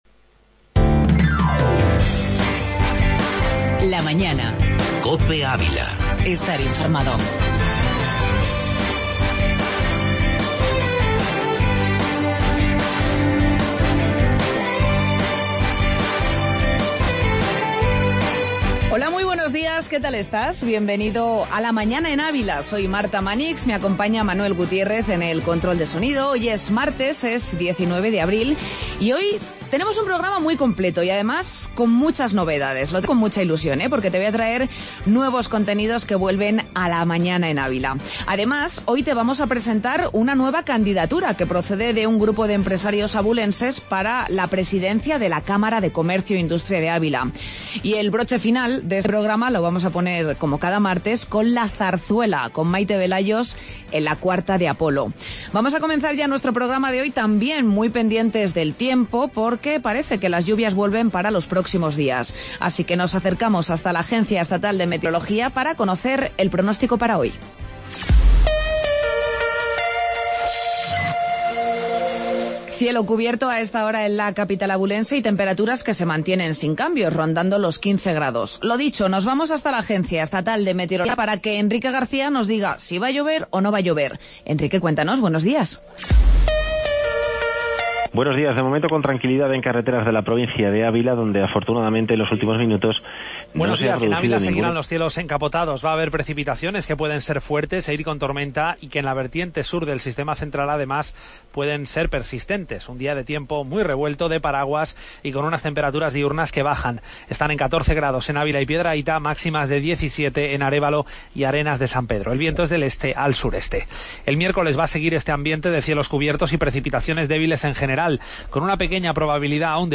AUDIO: Entrevista Camara de Comercio